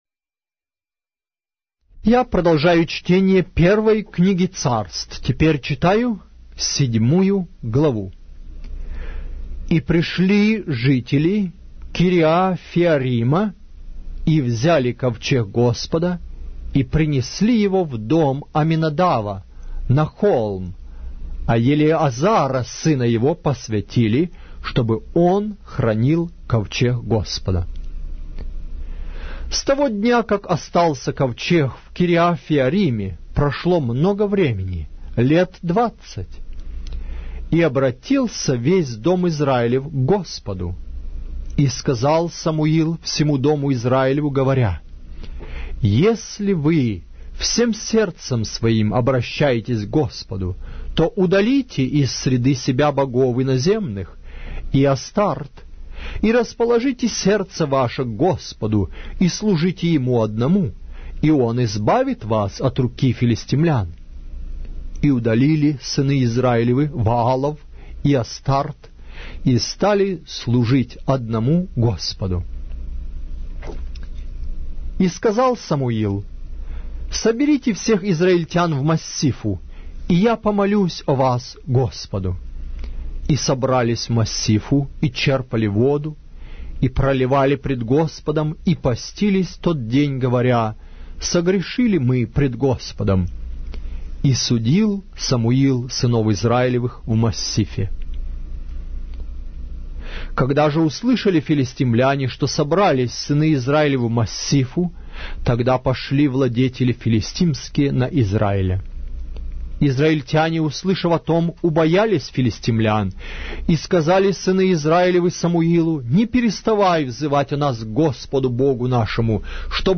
Глава русской Библии с аудио повествования - 1 Samuel, chapter 7 of the Holy Bible in Russian language